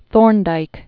(thôrndīk), Edward Lee 1874-1949.